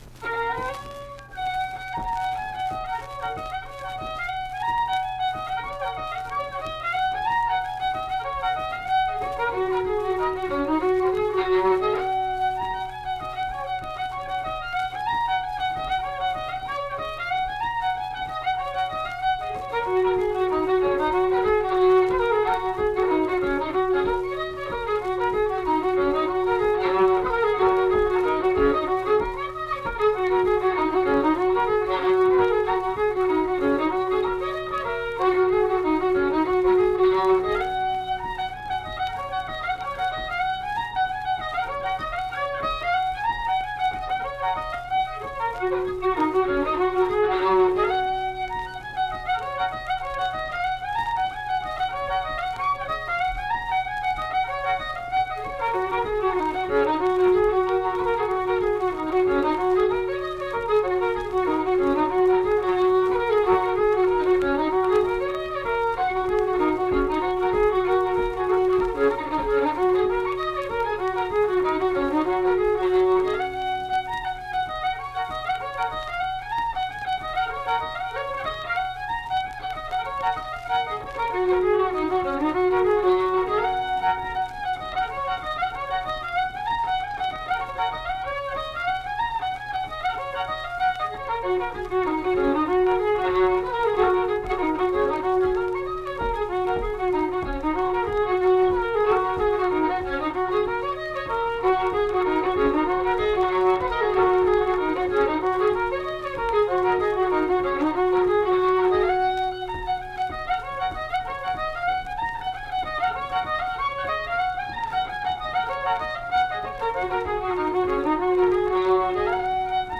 Unaccompanied fiddle music
Instrumental Music
Fiddle
Pocahontas County (W. Va.), Mill Point (W. Va.)